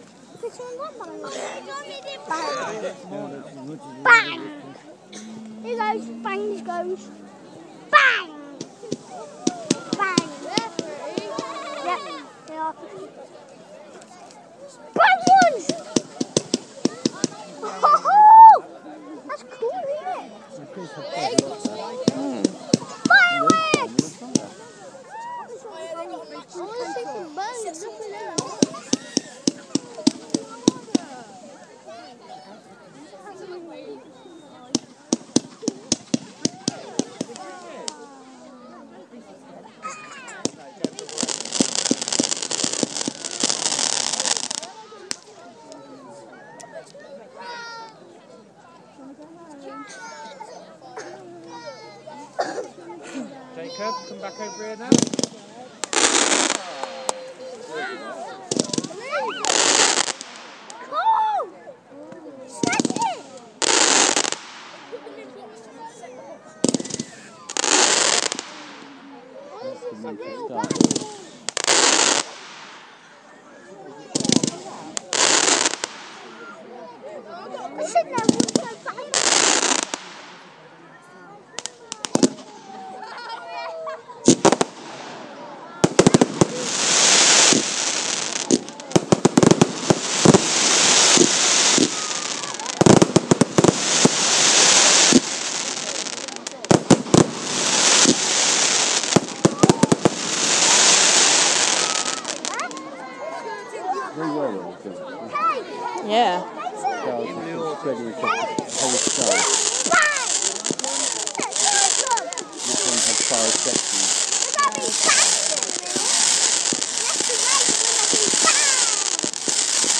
Amazing Pershore Fireworks part 1